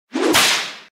دانلود آهنگ شلاق 1 از افکت صوتی اشیاء
جلوه های صوتی
دانلود صدای شلاق 1 از ساعد نیوز با لینک مستقیم و کیفیت بالا